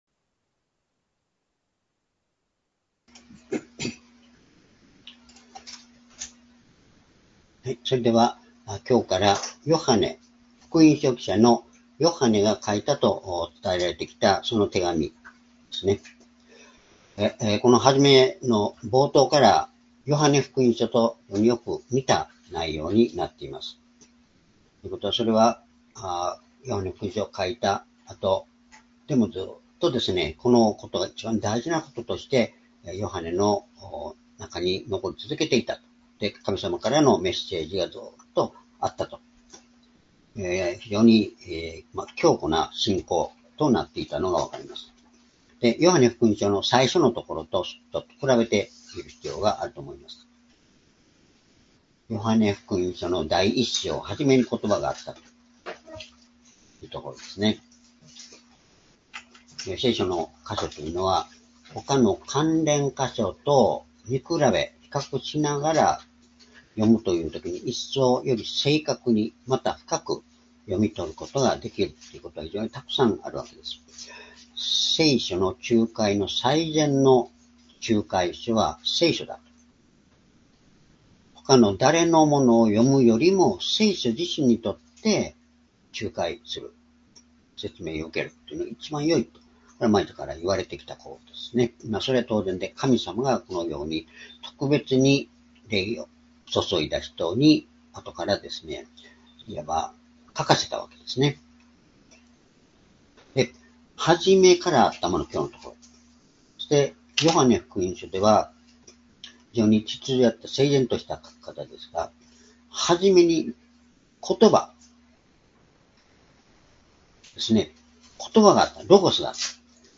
「私たちの交わり」－Ⅰヨハネ １章１節～４節－２０２５年6月22日（主日礼拝）